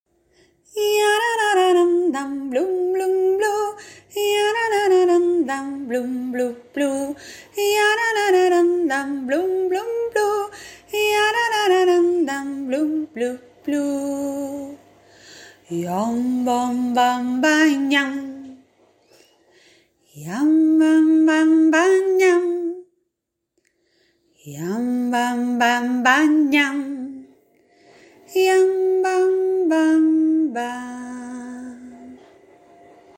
Mixolidio y doble.